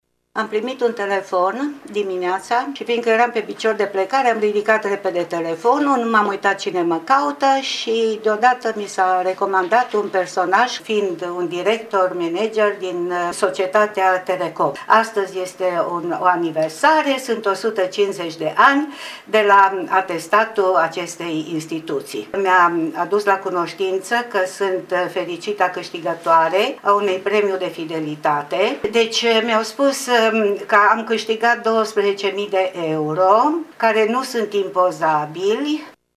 Una dintre victimele escrocilor povesteşte: